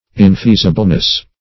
Search Result for " infeasibleness" : The Collaborative International Dictionary of English v.0.48: Infeasibleness \In*fea"si*ble*ness\, n. The state of quality of being infeasible; infeasibility.